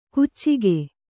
♪ kuccige